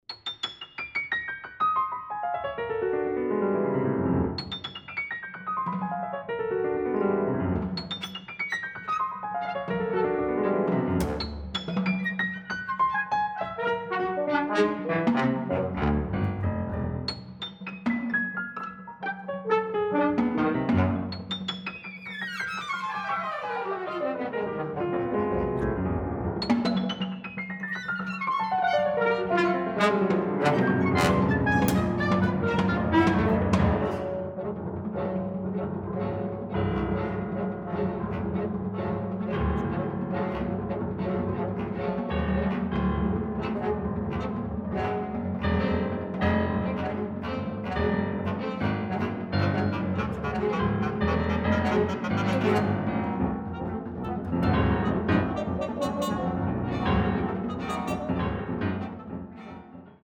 Pour piano et 14 instruments / For piano and 14 instruments